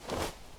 Backpack Sounds
action_open_inventory_4.ogg